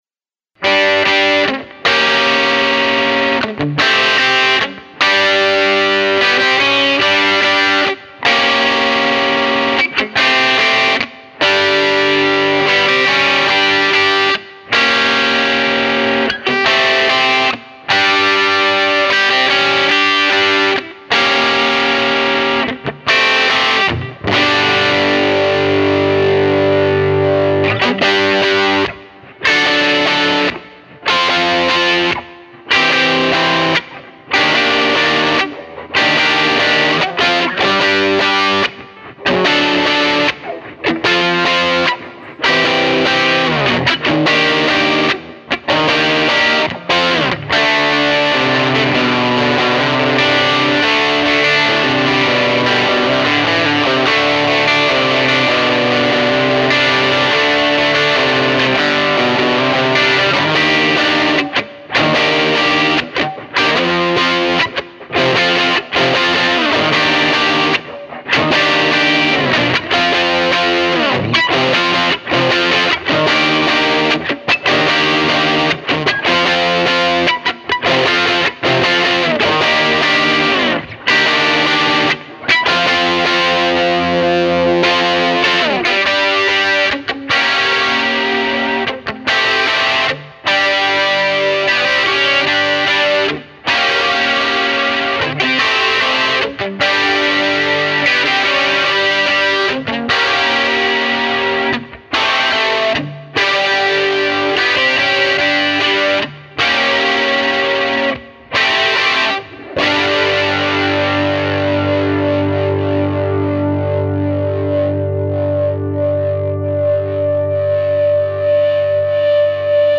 Volume was on 8 tone on 12. Hi input.